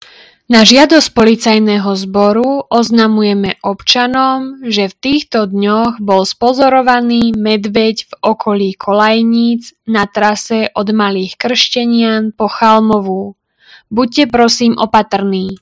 Hlásenie obecného rozhlasu - Upozornenie na výskyt medveďov v okolí obce - Čereňany
Hlásenie obecného rozhlasu – Upozornenie na výskyt medveďov v okolí obce